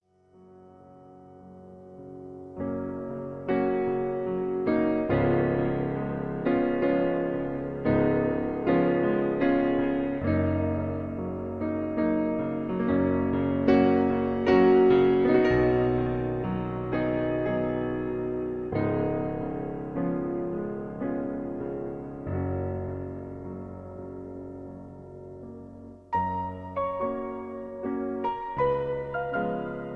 Das Intro zum gleichnamigen Konzertwalzer